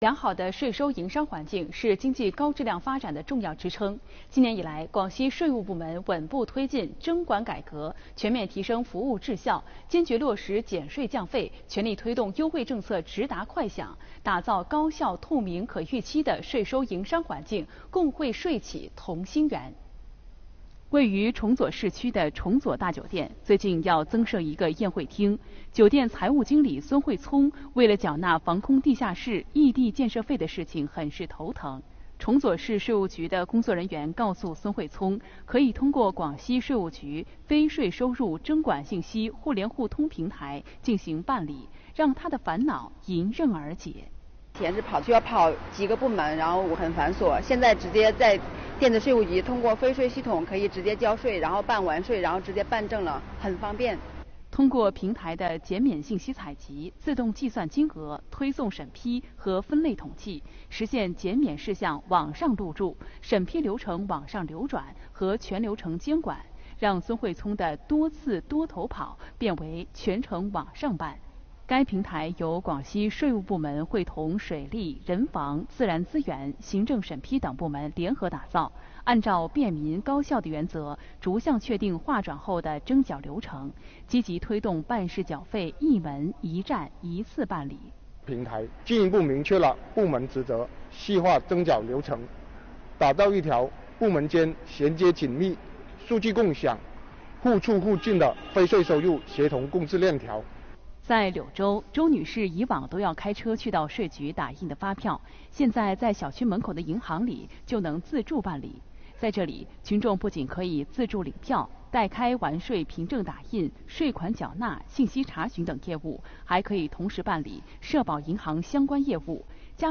【电视报道】广西税务：聚力优化服务 共绘“同心圆”